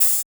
Metro OH2.wav